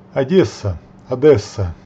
Odesa (Oekraïens: Оде́са, Odésa, [ɔˈdɛsɐ], luister (hulp·inligting)), voor die Russiese inval in Oekraïne ook Odessa (Russies: Одесса, [ɐˈdʲes(ː)ə],
Ru-Одесса.ogg